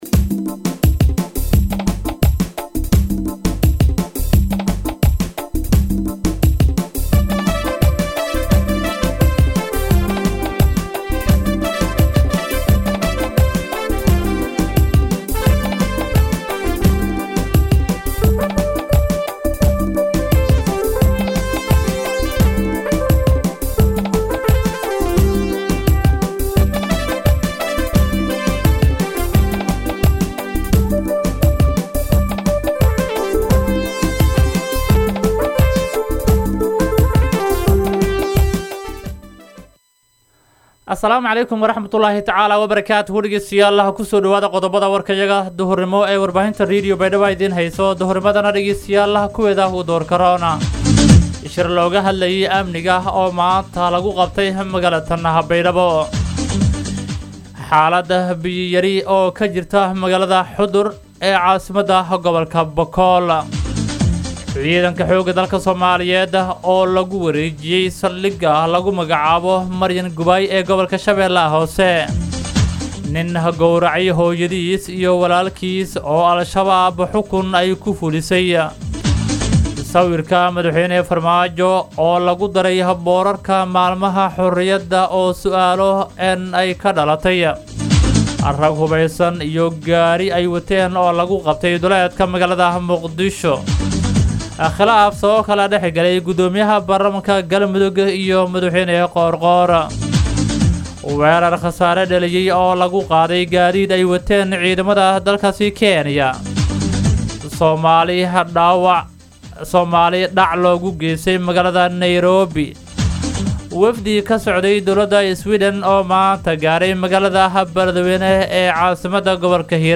BAYDHABO–BMC:–Dhageystayaasha Radio Baidoa ee ku xiran Website-ka Idaacada Waxaan halkaan ugu soo gudbineynaa Warka maanta ee ka baxay Radio Baidoa.